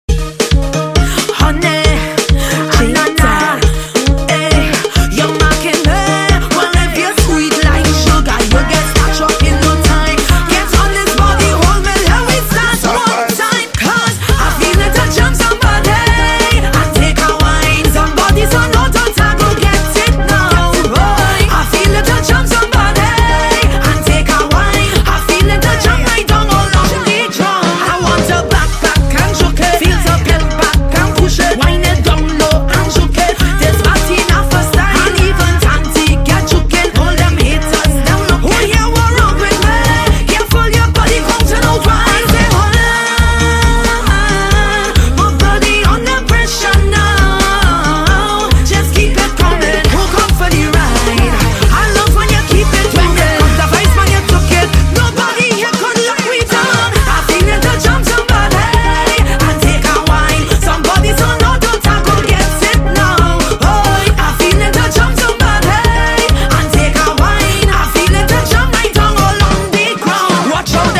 THE BEST SOCA RIDDIMS IN 2025 - THE BEST HITS